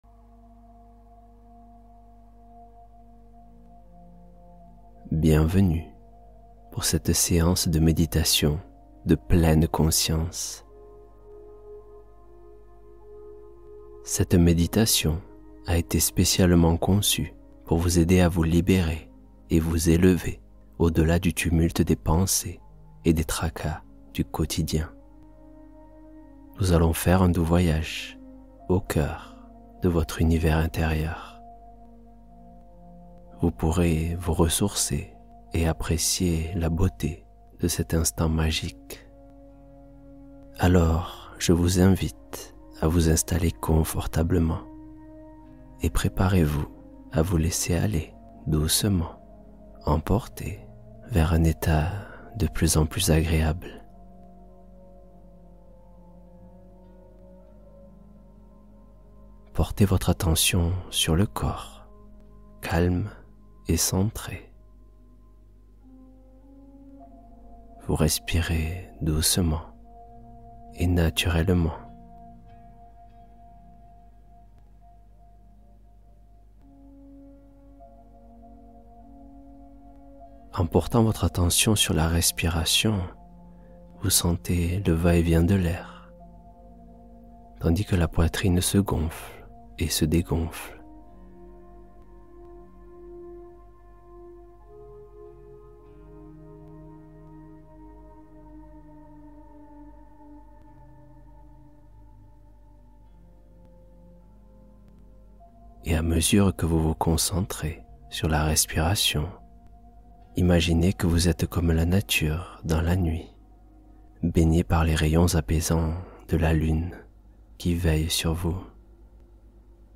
Histoire guidée : glisser vers un sommeil apaisé